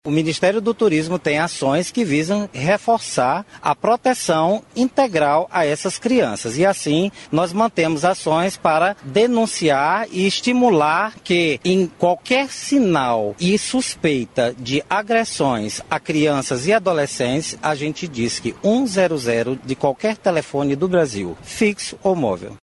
Ouça aqui declaração na qual Vinicius Lummertz frisa que todos os brasileiros podem participar da iniciativa.